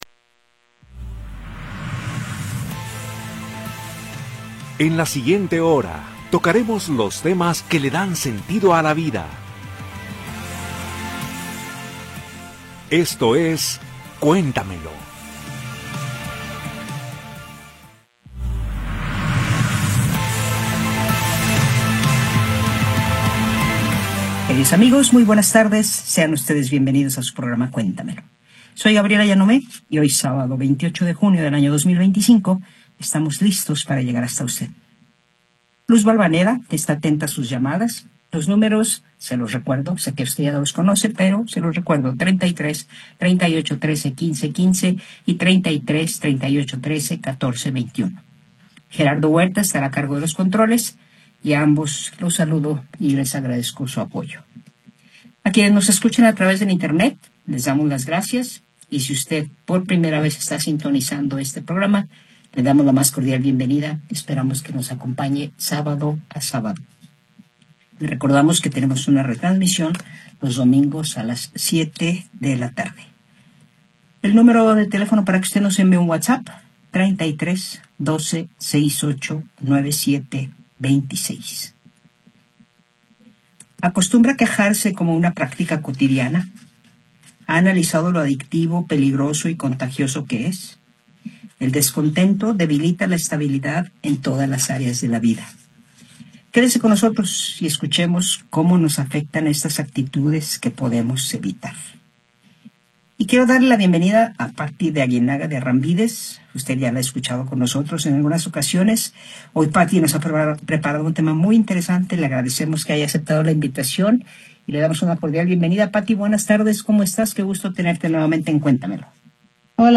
Programa transmitido el 28 de Junio de 2025.